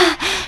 FEMALE PANT.wav